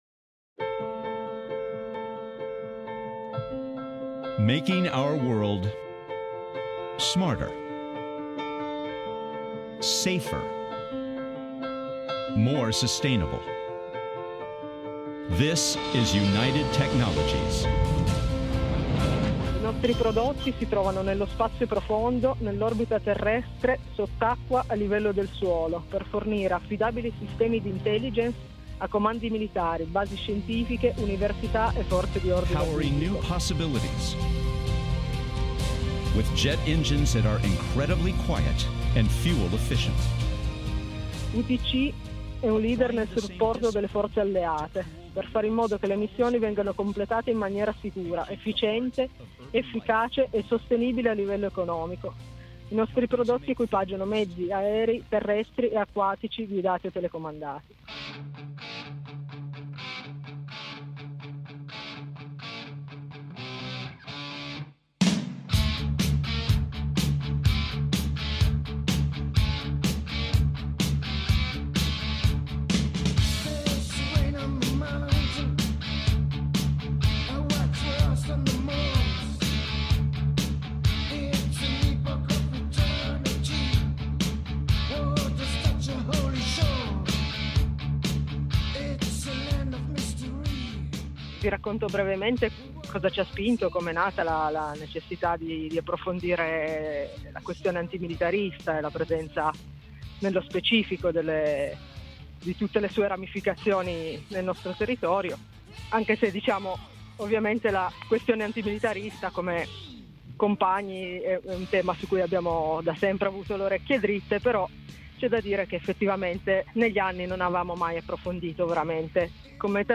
Di questo e di altro abbiamo discusso con una compagna di Alpi Libere.